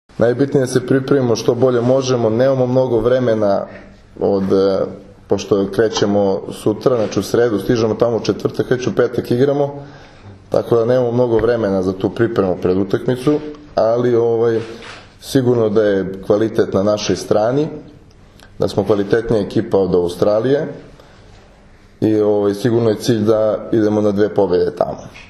IZJAVA NIKOLE KOVAČEVIĆA